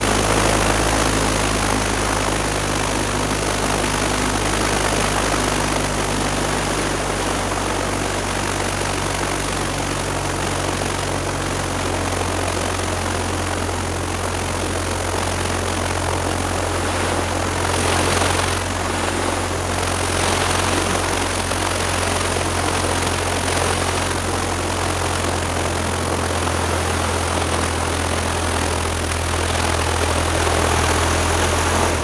Propeller_Plane-44k_1.R.wav